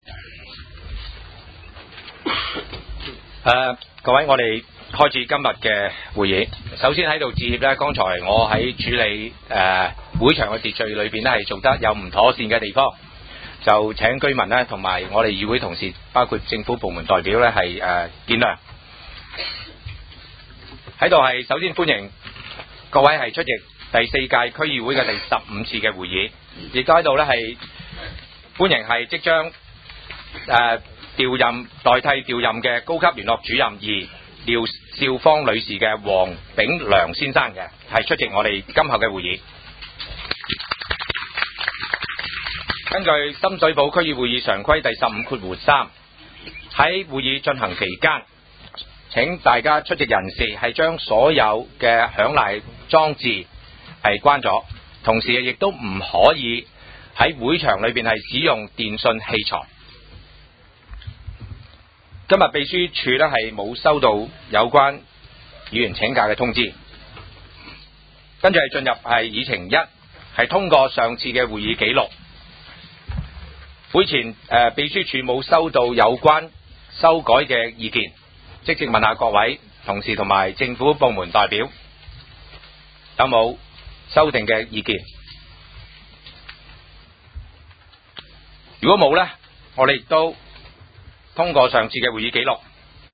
区议会大会的录音记录
地点: 会议室，深水埗民政事务处,